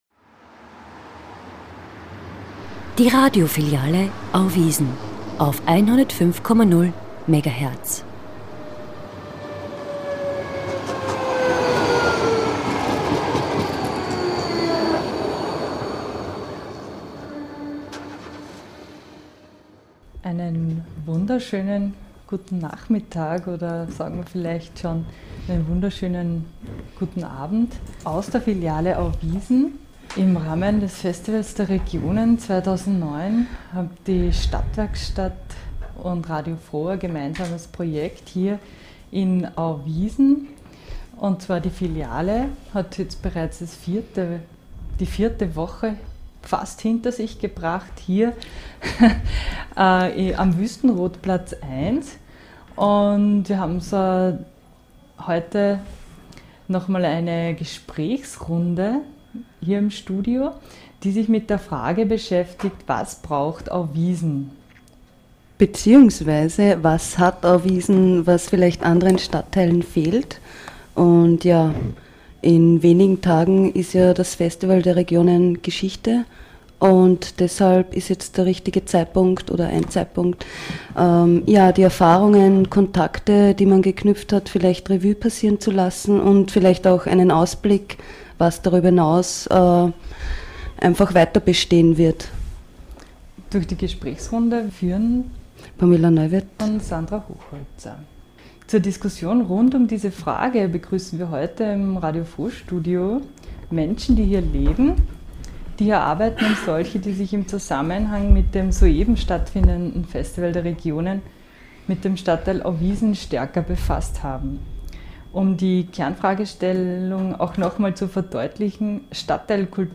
.....aus der Filiale Auwiesen auf Radio FRO 105.0 MHz am Samstag den 30.Mai von 18-20 Uhr
Live aus Auwiesen - Brunnen am Dorfplatz